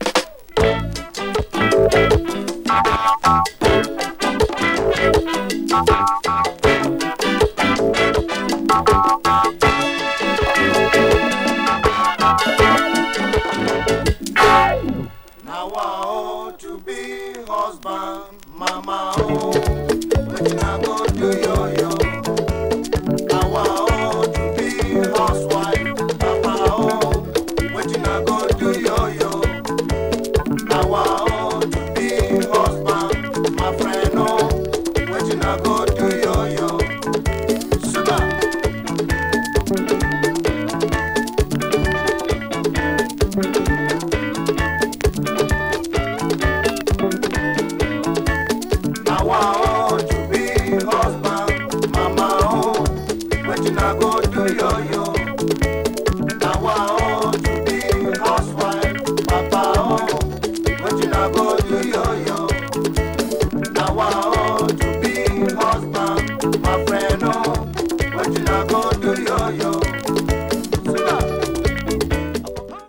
プレス国 : ガーナ
70s AFRO FUNKY 詳細を表示する